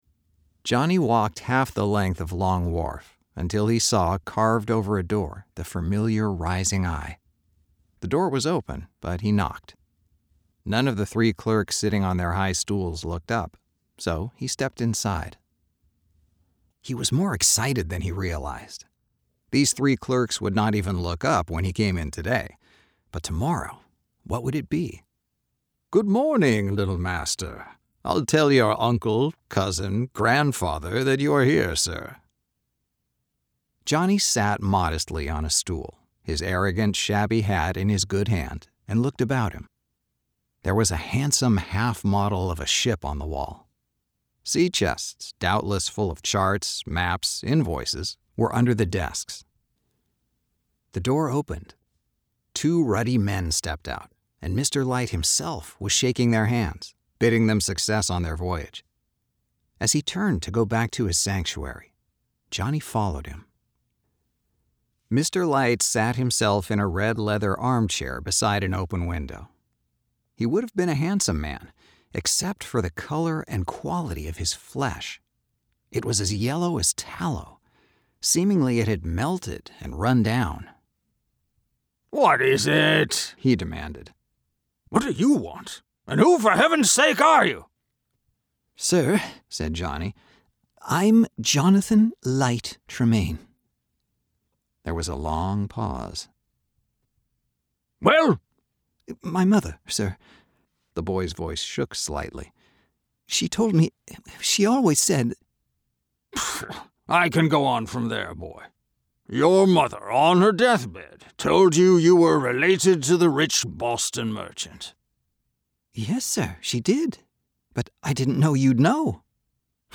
Voice acting for brands with a brighter message
audiobook – young adult
California and works from his broadcast-quality home studio.